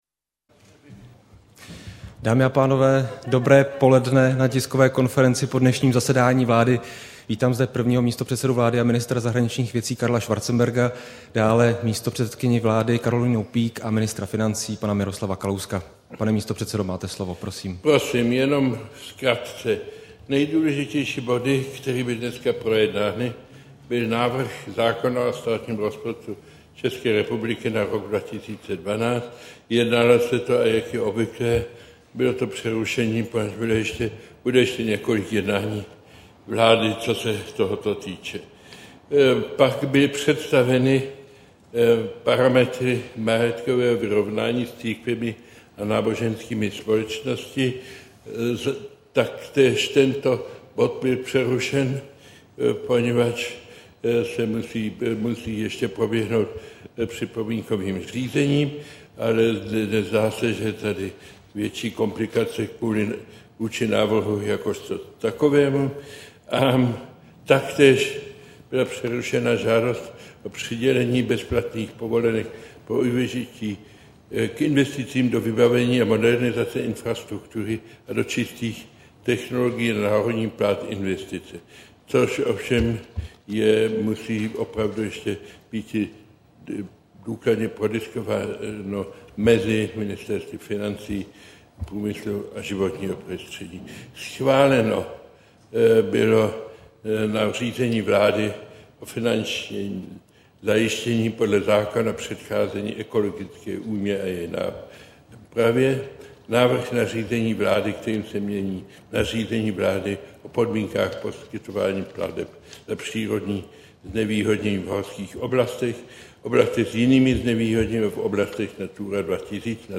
Tisková konference po jednání vlády, 14. září 2011